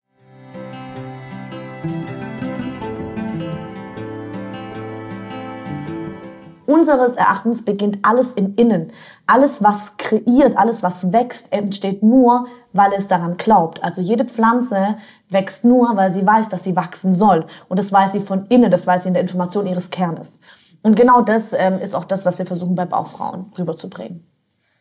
Quasi ein Studiotalk zuhause bei unserem Gast.